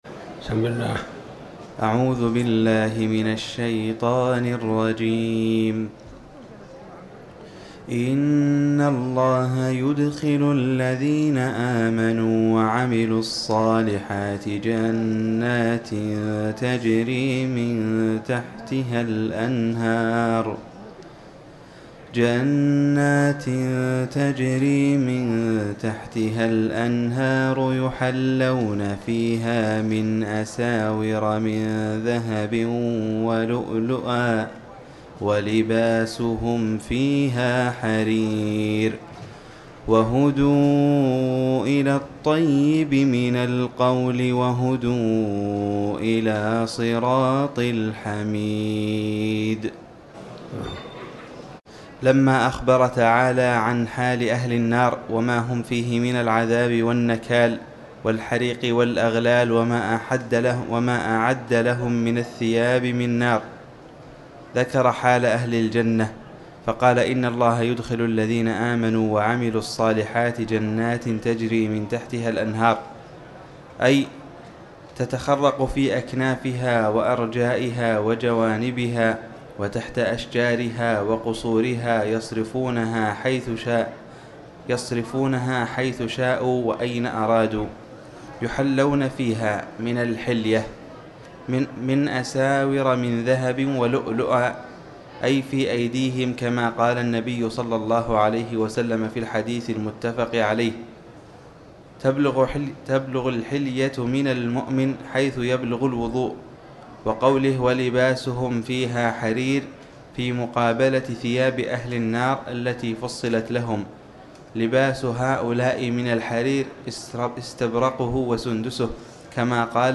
تاريخ النشر ١٦ ذو القعدة ١٤٤٠ هـ المكان: المسجد الحرام الشيخ